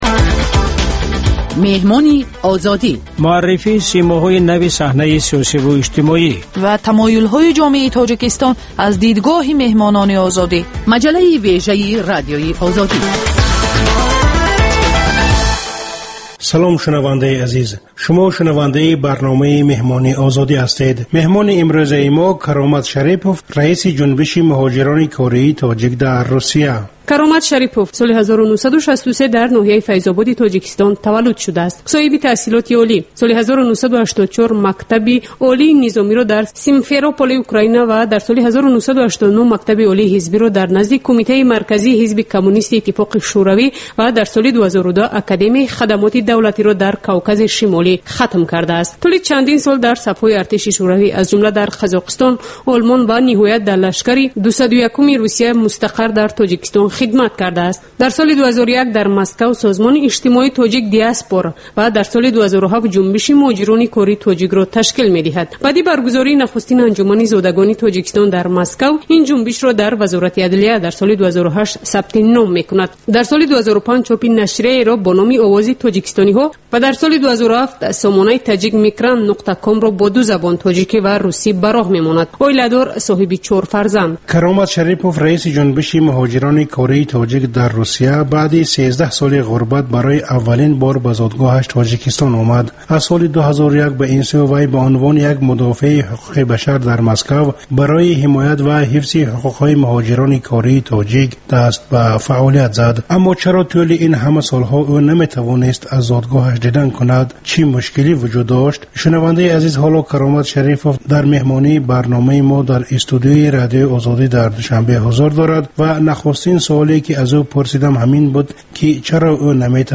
Гуфтугӯи ошкоро бо чеҳраҳои саршинос, намояндагони риштаҳои гуногун бо пурсишҳои ғайриодӣ.